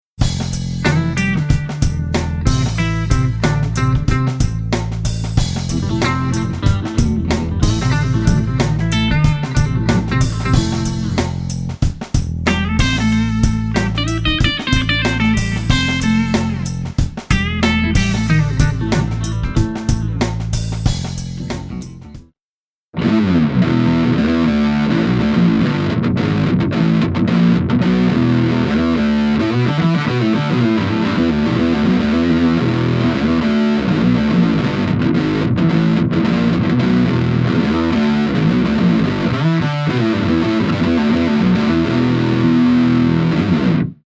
OC-3: 超级八度 - 世界第一款复调八度踏板。
oc-3_sound_check.mp3